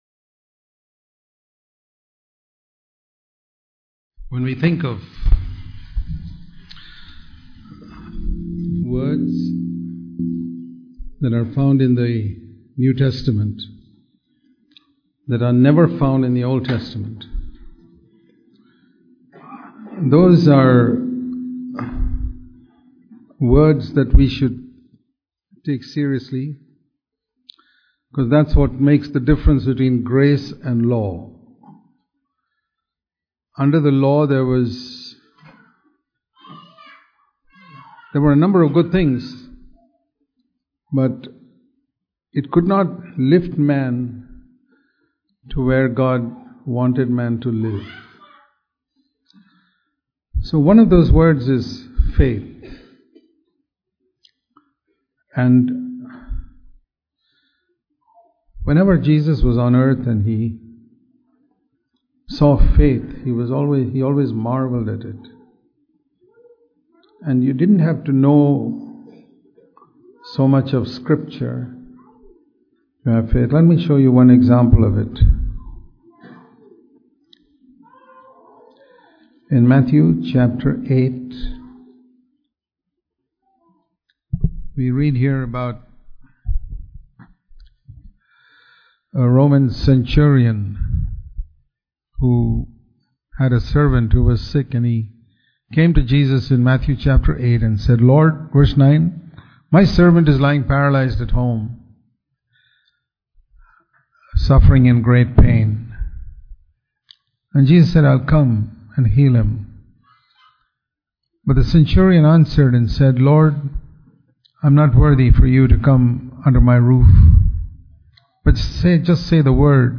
Faith and Humility Dubai Meetings February 2017